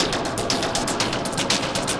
Index of /musicradar/rhythmic-inspiration-samples/120bpm
RI_DelayStack_120-02.wav